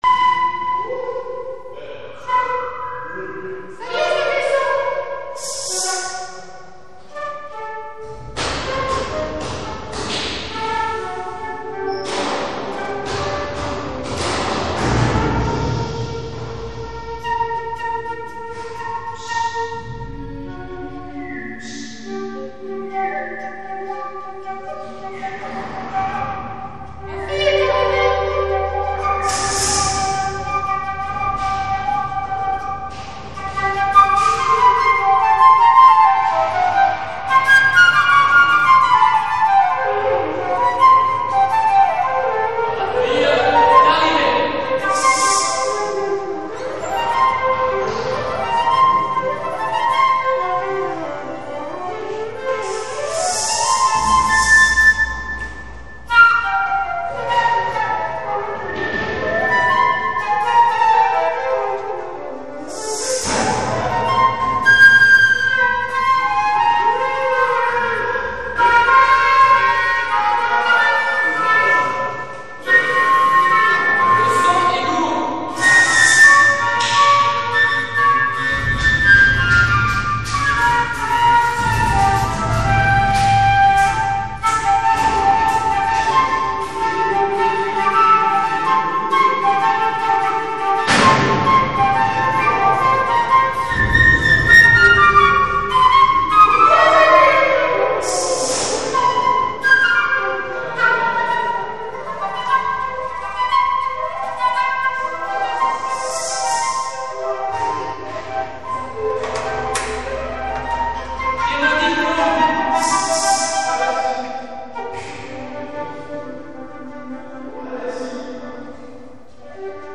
Dans cette page-rubrique seront publiées des expériences de musique improvisée notamment réalisées avec le concours du Chaosmeetik Bobarouf Ensemble.
Extrait d’Ozone B, grand concerto pour flûte et escalier vacchinien :
(Solo de flûte. Jazzy man non troppo.)